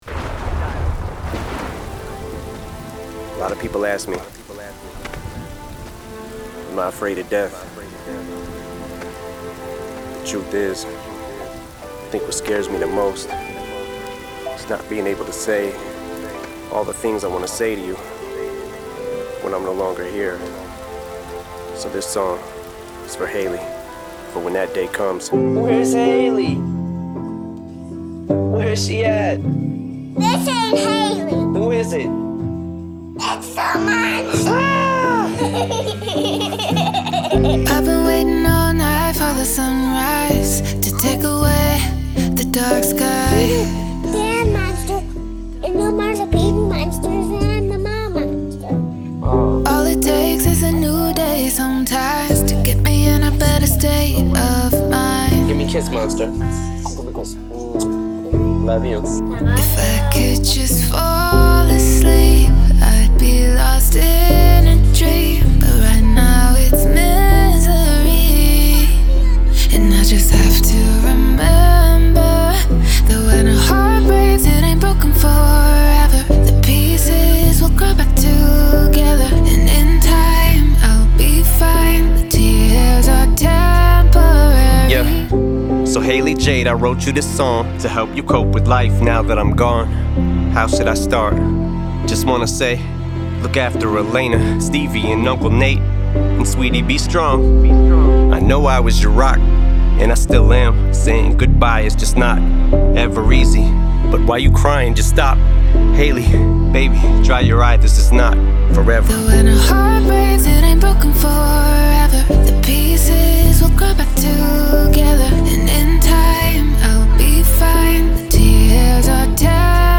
• Жанр: Hip-Hop, Rap